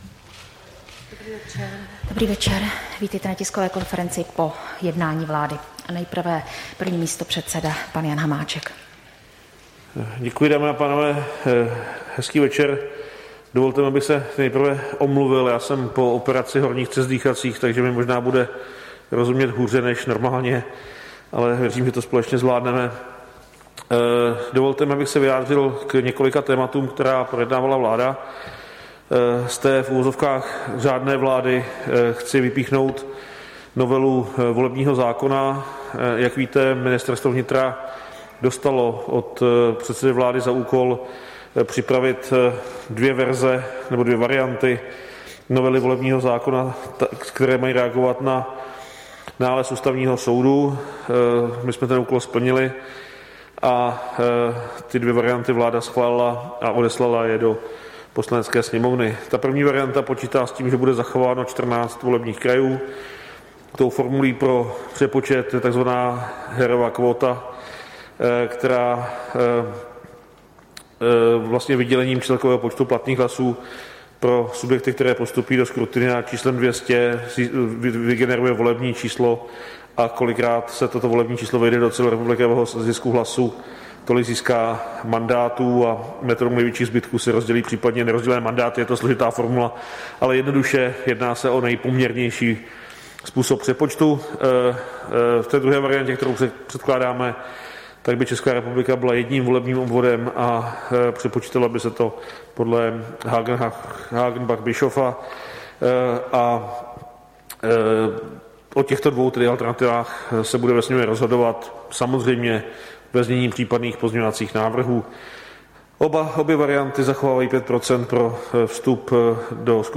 Tisková konference po jednání vlády, 22. února 2021